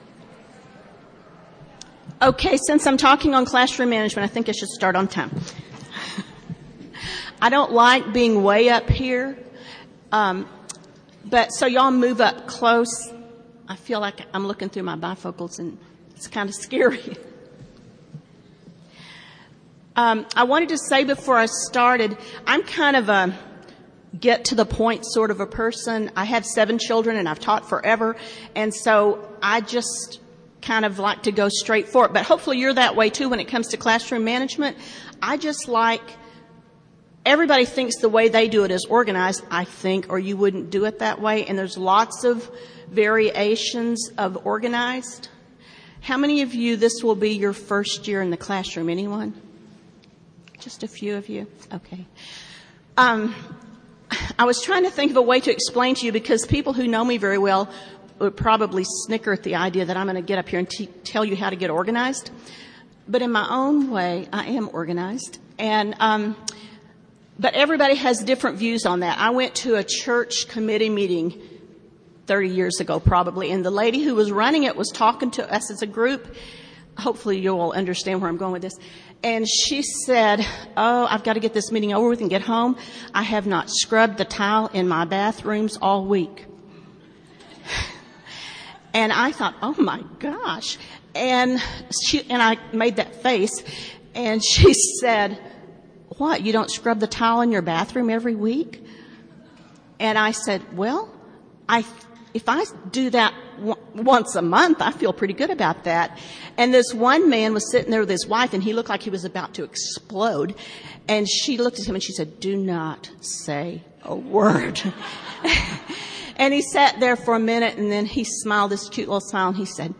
Jan 31, 2019 | All Grade Levels, Conference Talks, General Classroom, Library, Media_Audio, Workshop Talk | 0 comments